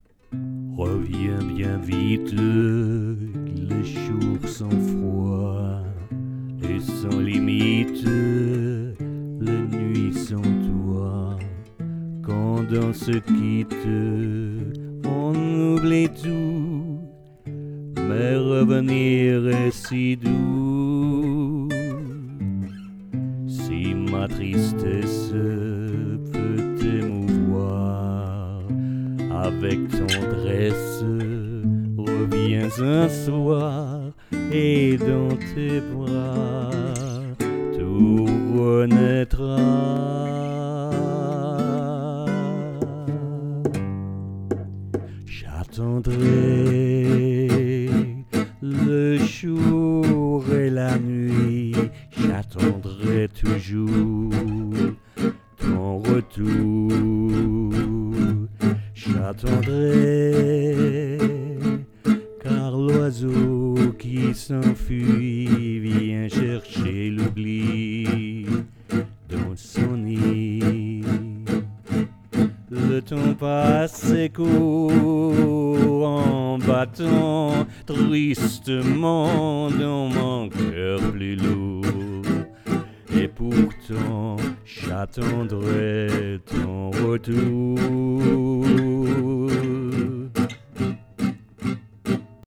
Here is a vocal demo.
But I think there should be more "acting" the emotion, which is difficult to record, because I was very very close to the mic, with low voice and low energy, almost with my speaking voice.
Stanza 2 and verse Demo.WAV
stanza-2-and-verse-demo-wav.wav